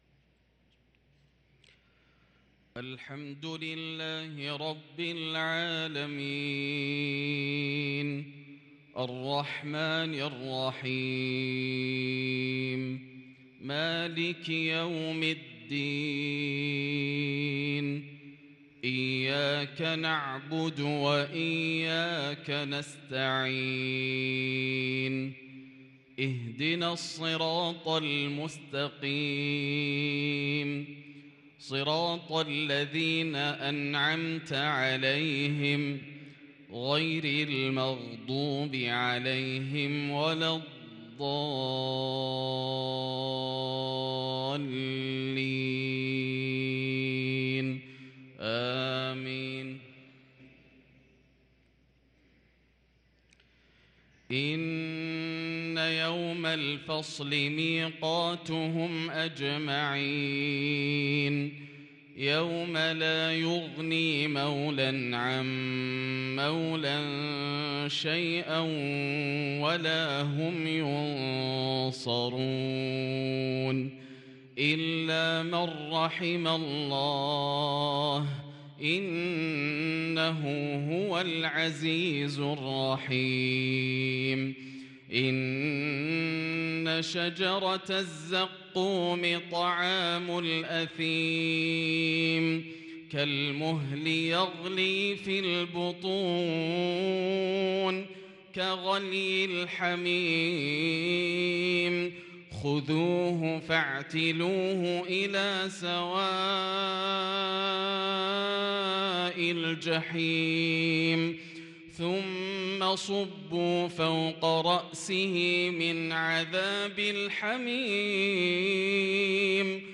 صلاة المغرب للقارئ ياسر الدوسري 16 ربيع الآخر 1444 هـ
تِلَاوَات الْحَرَمَيْن .